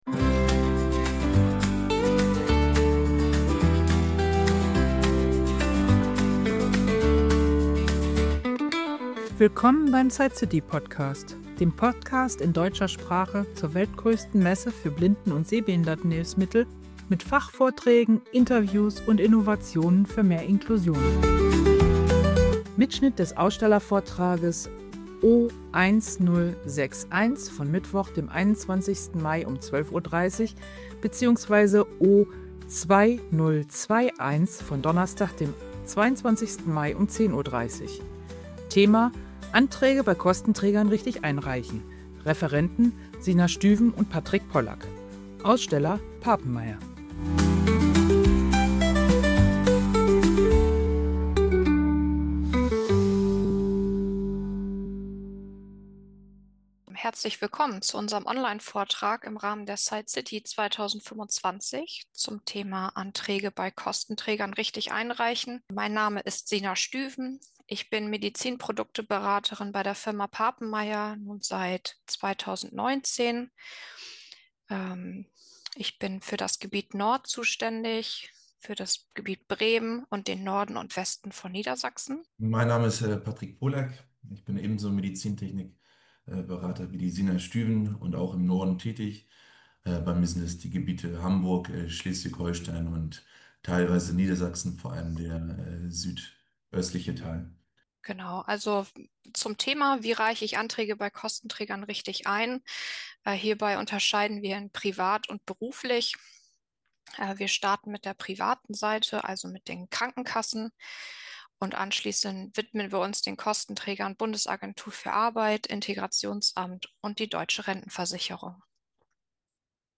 SightCity 2025: Anträge bei Kostenträgern richtig einreichen ~ SightCity DE Podcast
Der Vortrag behandelt sowohl die private Versorgung über Krankenkassen als auch die berufliche Förderung durch Agentur für Arbeit, Integrationsamt und Deutsche Rentenversicherung. Mit konkreten Formularnummern, Ablaufbeschreibungen und einer ausführlichen Fragerunde bietet diese Folge wertvolle Orientierung für alle, die Hilfsmittel beantragen möchten.